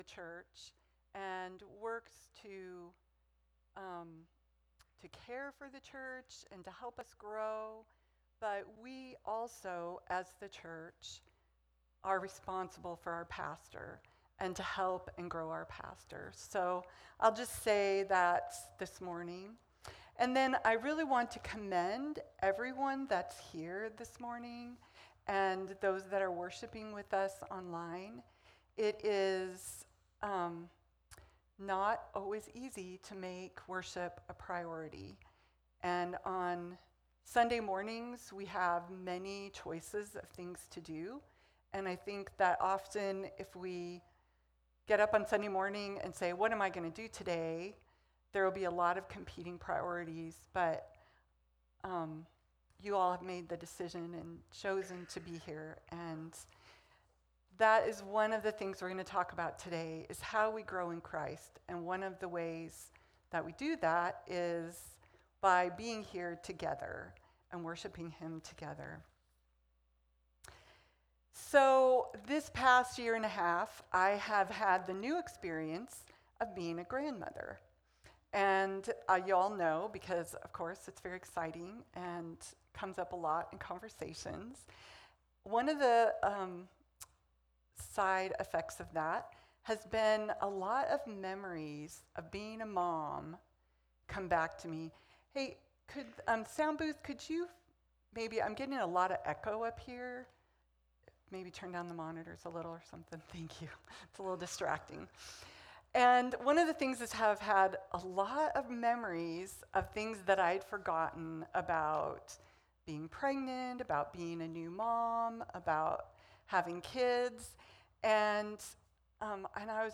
May 18th, 2025 - Sunday Service - Wasilla Lake Church